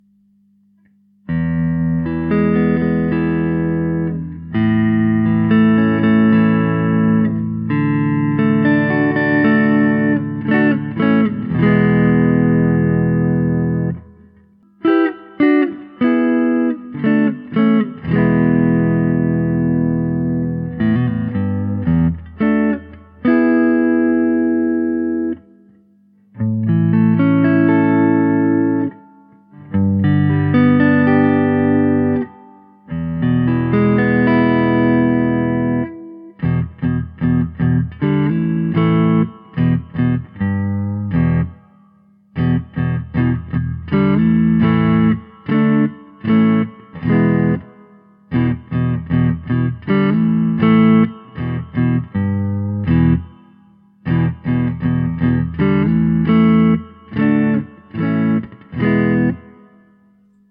The mark II Seraphim improves on the original Seraphim with tapped tones that are a little fatter and bolder while the full tones are clearer and brighter.
The tapped sound is open and clear but warm with a well rounded bass. The full sound is slightly hotter than traditional vintage tone for those who prefer a fatter tone
The standard sound samples are of Mark II Seraphims.